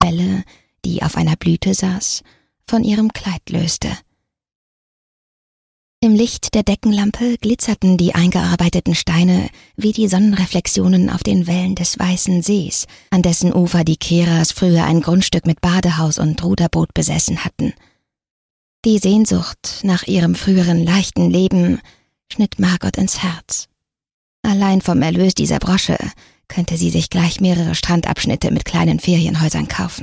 Die Hüterin der verlorenen Schätze - Hörbuch